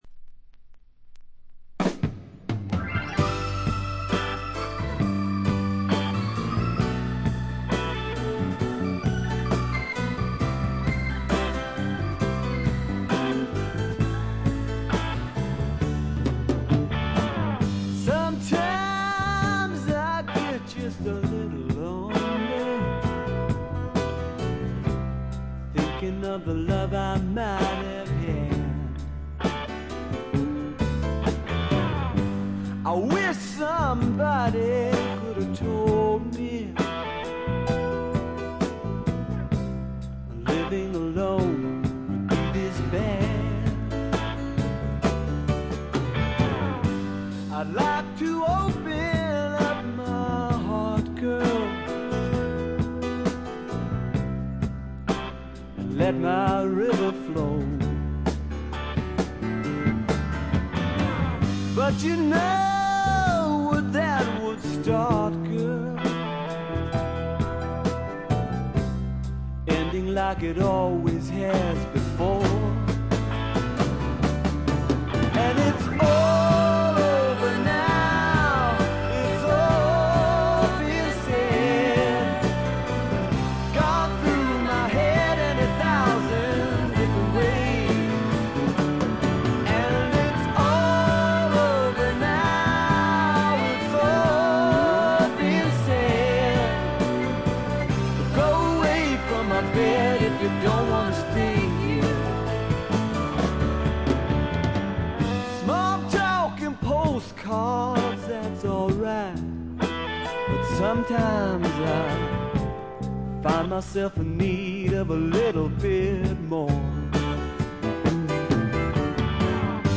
これ以外は軽いチリプチ少々、散発的なプツ音2-3回という程度で良好に鑑賞できると思います。
試聴曲は現品からの取り込み音源です。
Recorded at Larrabee Sound , Holywood , California